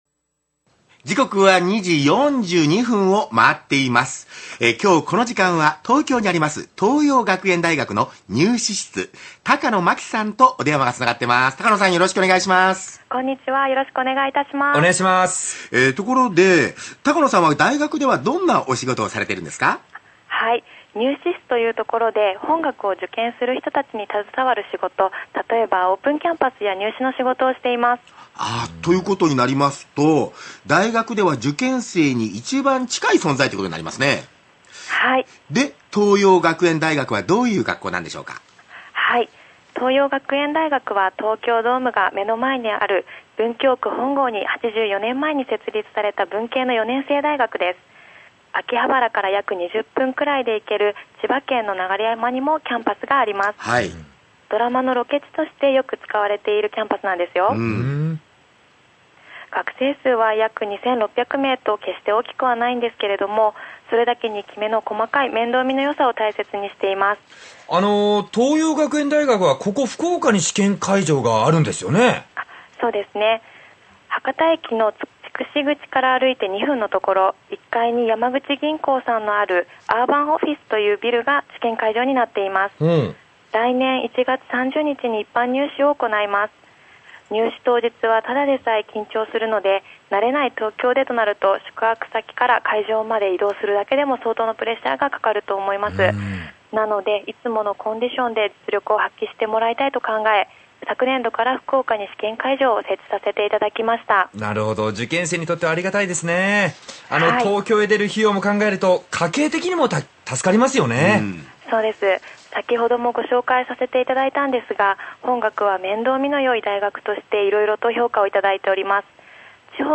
福岡県のラジオ局・ＲＫＢ毎日放送（AM1278KHz）で、地方入試ＰＲ。